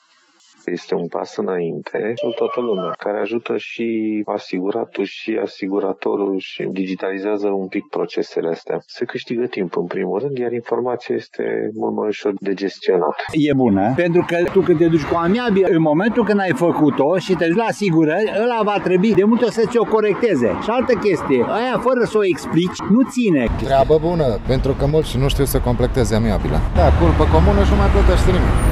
Pentru conducătorii auto din Tg.Mureș e destul de neclar cum va funcționa această aplicație: